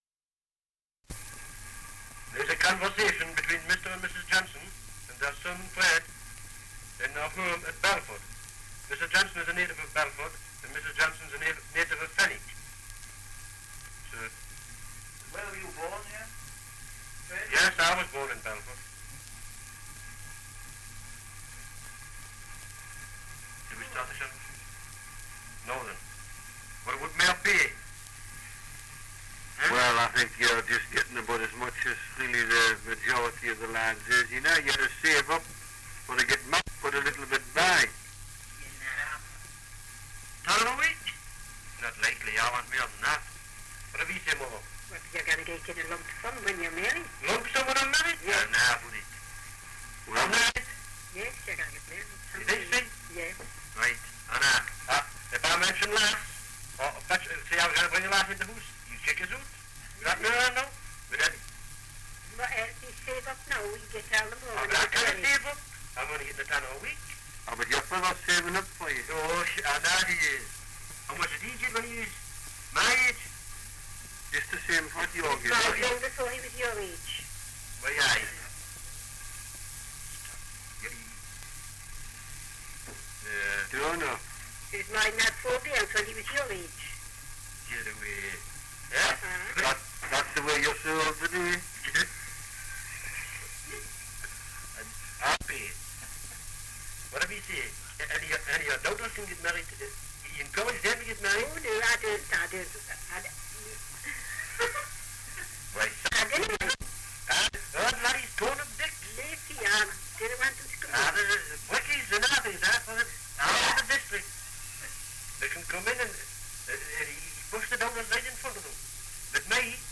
2 - Dialect recording in Belford, Northumberland
78 r.p.m., cellulose nitrate on aluminium